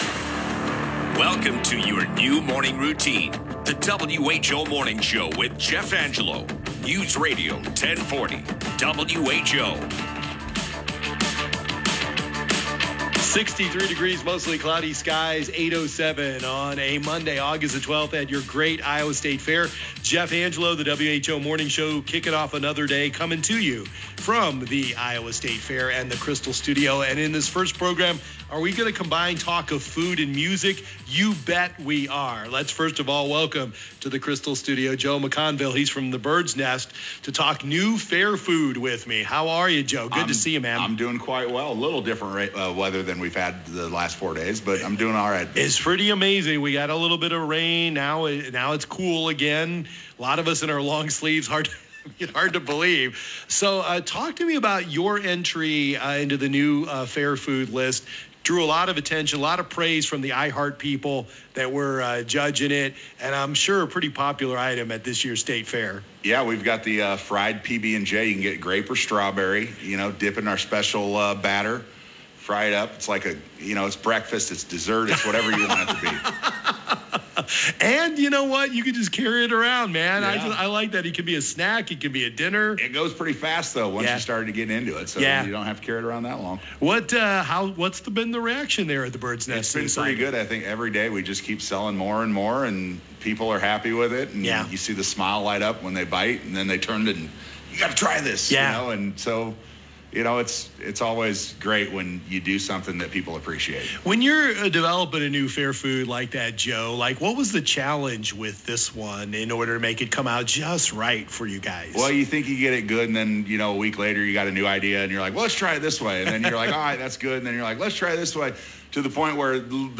at the Iowa State Fair